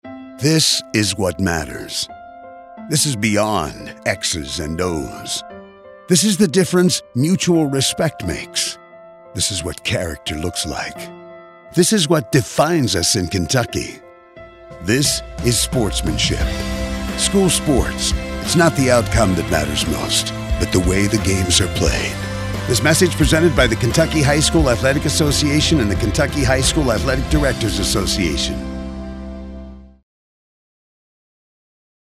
18-19 Radio – Public Service Announcements